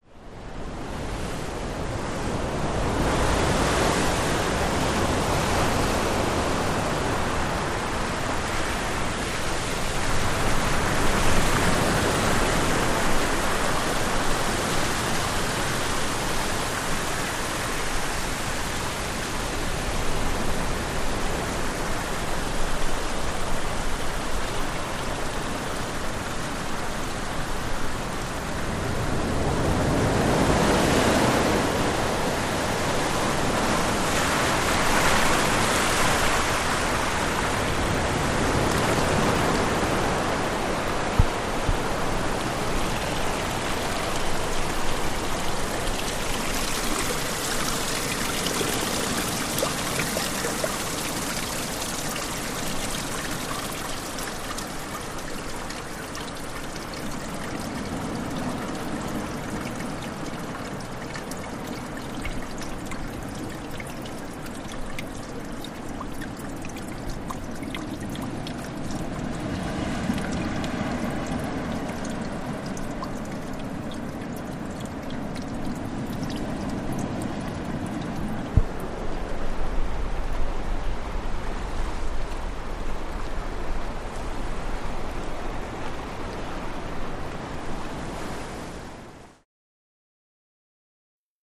Wave Crashes
Close Up On Mediterranean Sea, Water Spraying Through Rocky Cracks.